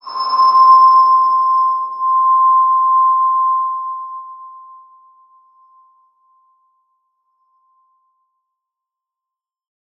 X_BasicBells-C4-mf.wav